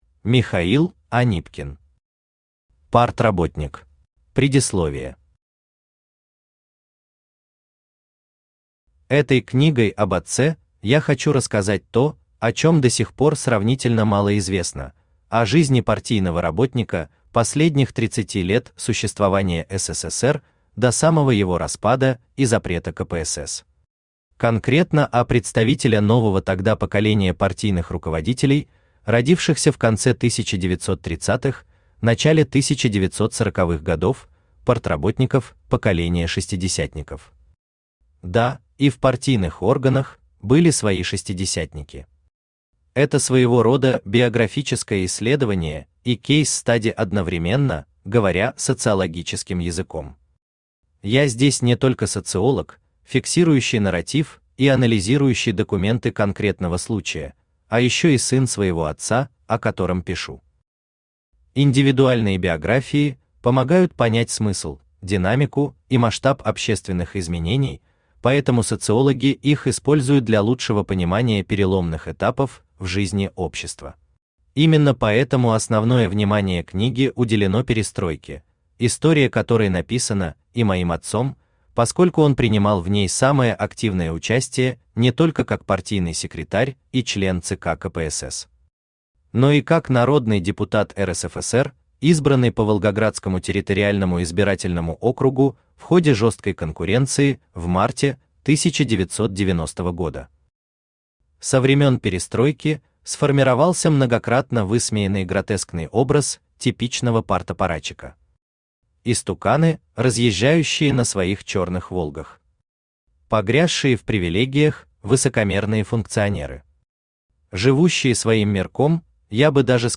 Аудиокнига Партработник | Библиотека аудиокниг
Aудиокнига Партработник Автор Михаил Александрович Анипкин Читает аудиокнигу Авточтец ЛитРес.